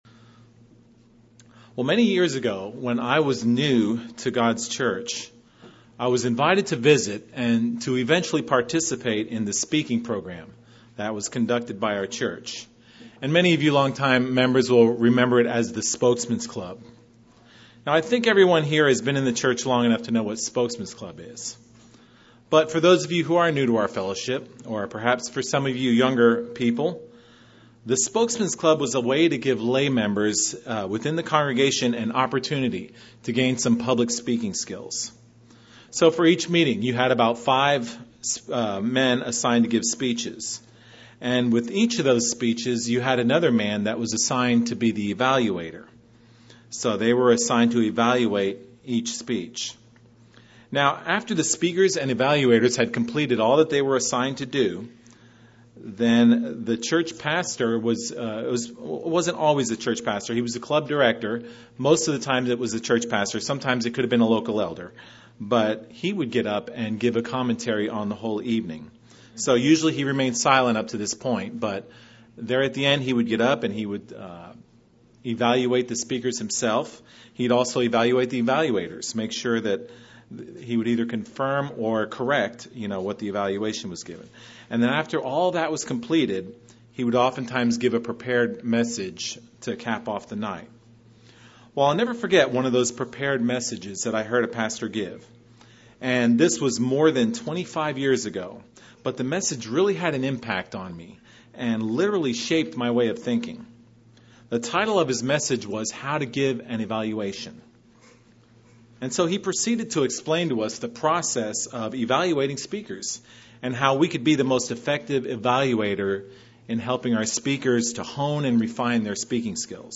This sermon discusses how to make a true evaluation.